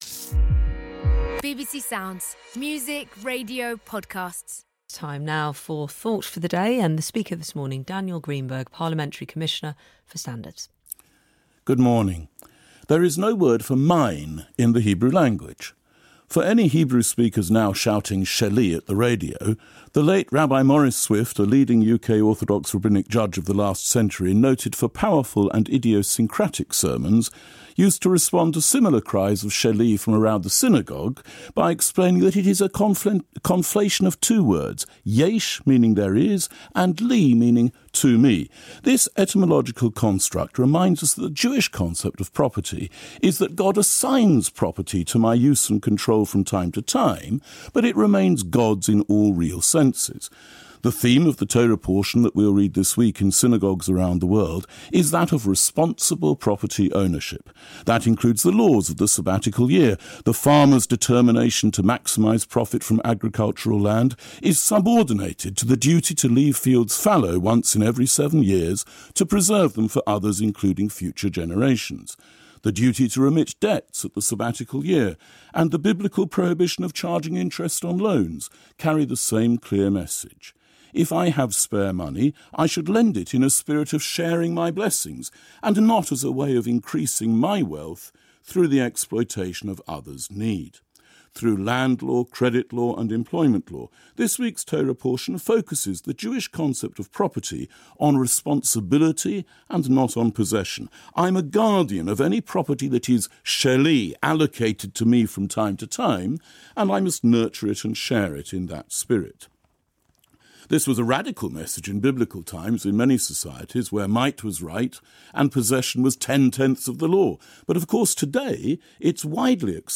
On Thursday 22nd May 2025, I presented a session on BBC Radio 4 Thought for the Day on the subject of “Possession as Responsibility”.